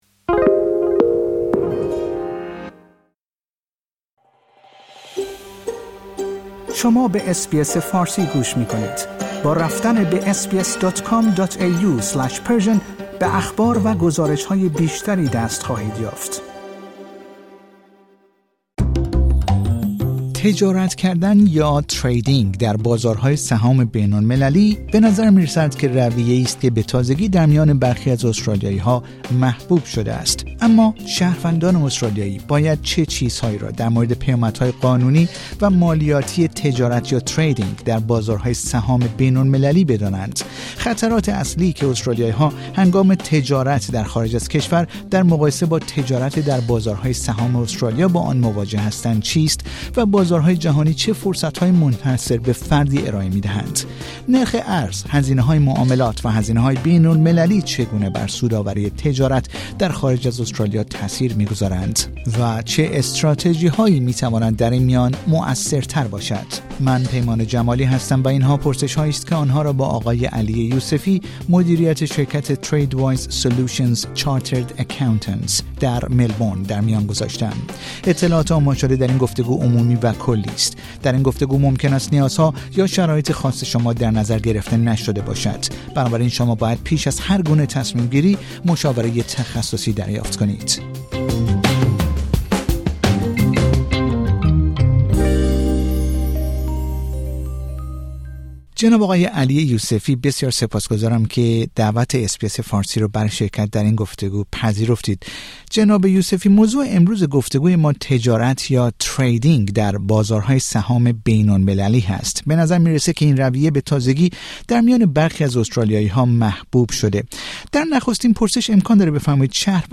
اطلاعات عنوان شده در این گفتگو، عمومی و کلی است.